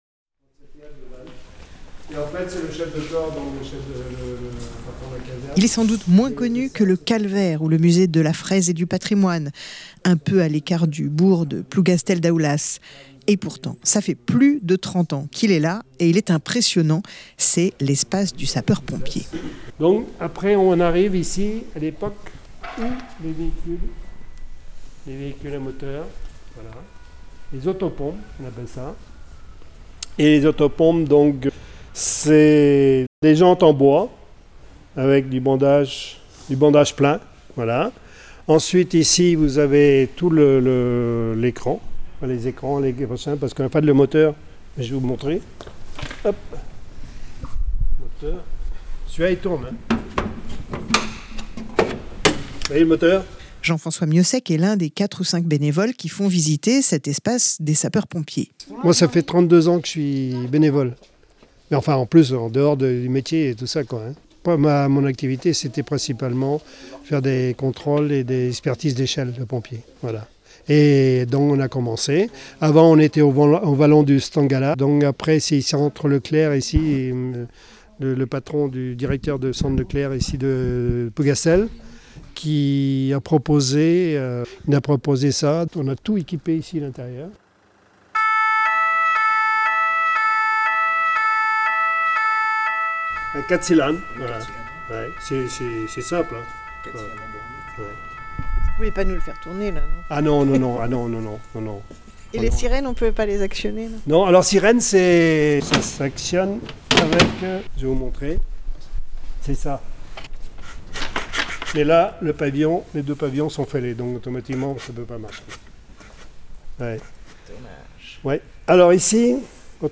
Visite de l'espace du sapeur-pompier, un musée qui collecte les objets du début du 20e siècle à nos jours à Plougastel-Daoulas
LEMRUB-visite-musee-sapeur-pompier-Plougastel-Daoulas.mp3